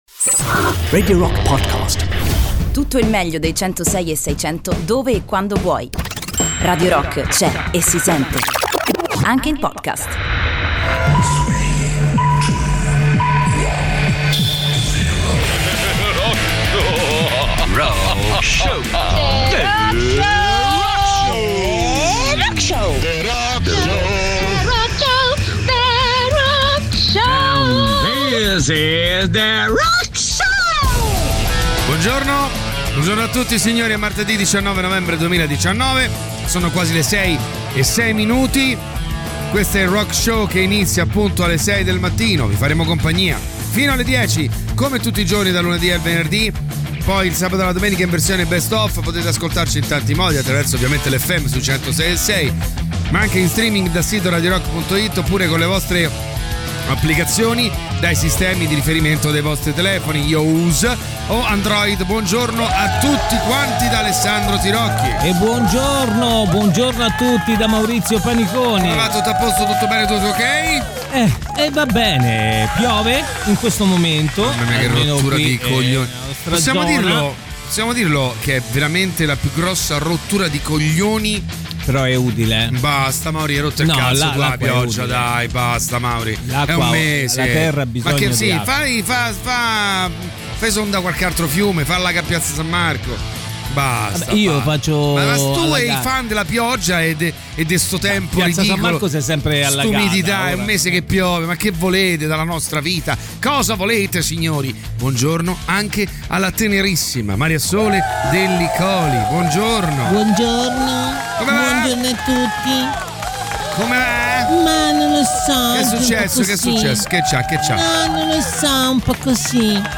in diretta dalle 06.00 alle 08.00 dal Lunedì al Venerdì sui 106.6 di Radio Rock.